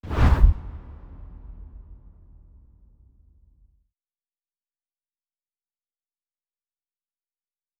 Whoosh 3.wav